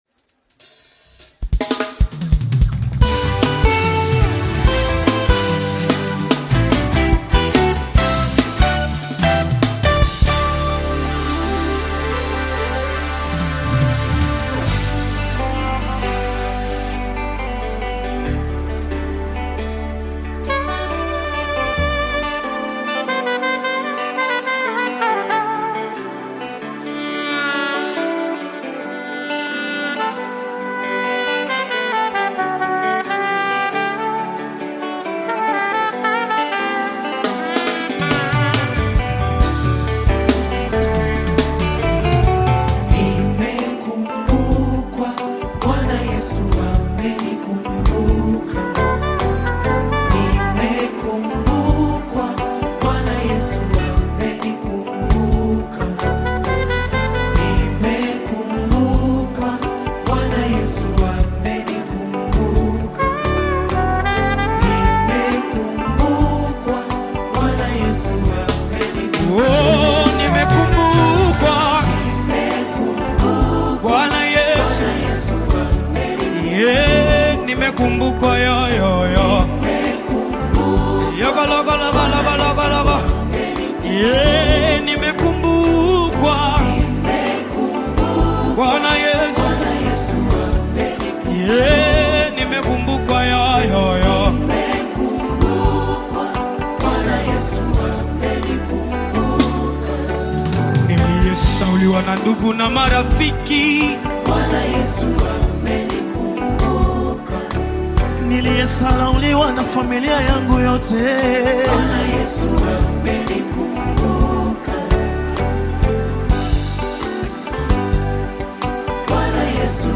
Tanzanian Gospel Choir
Gospel song